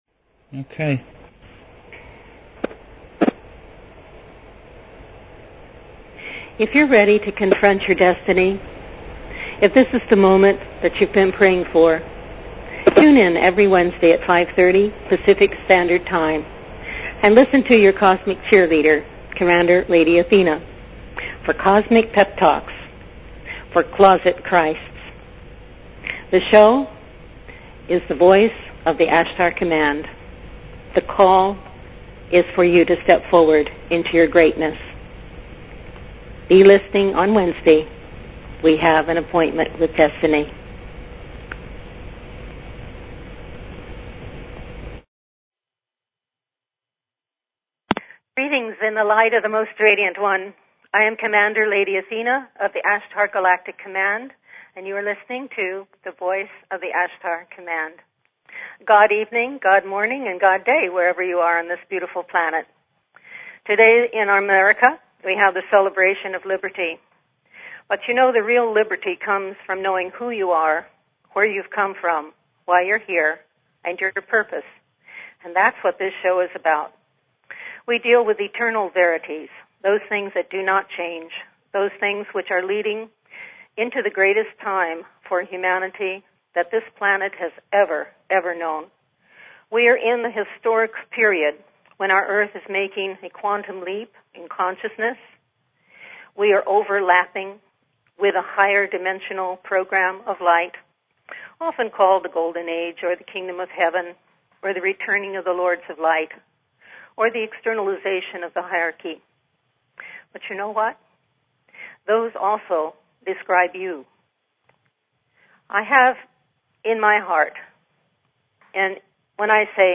Talk Show Episode, Audio Podcast, The_Voice_of_the_Ashtar_Command and Courtesy of BBS Radio on , show guests , about , categorized as
Show Headline The_Voice_of_the_Ashtar_Command Show Sub Headline Courtesy of BBS Radio FREEDOM: Note: There is an interruption in the early part of the show lasting a couple minutes & music is then inserted , so hang in there because the transmission from the Ashtar Command continues.